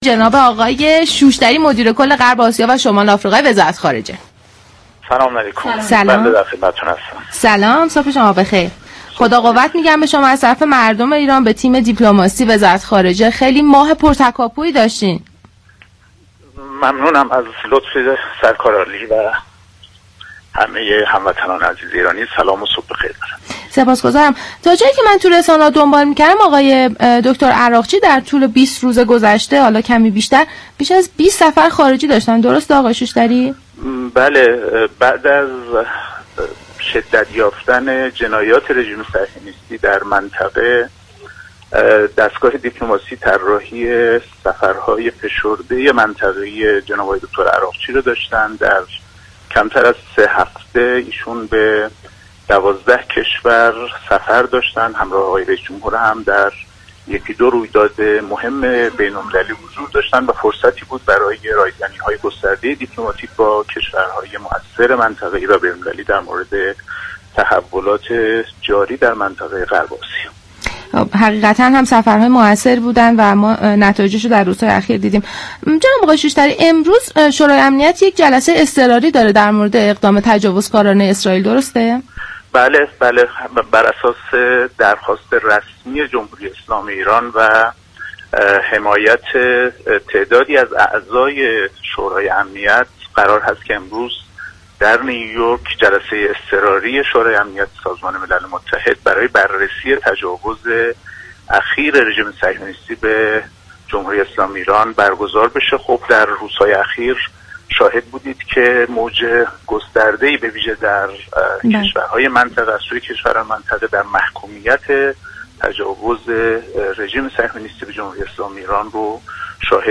به گزارش پایگاه اطلاع رسانی رادیو تهران، مهدی شوشتری مدیركل غرب آسیا و شمال آفریقای وزارت امور خارجه در گفت و گو با «بام تهران» اظهار داشت: بعد از شدت یافتن جنایت‌های رژیم صهیونیستی در منطقه، عراقچی وزیر امور خارجه برای رایزنی‌های گسترده دیپلماسی با كشورهای بین المللی و منطقه درخصوص تحولات جاری در منطقه غرب آسیا طی سه هفته به 12 كشور سفر كردند.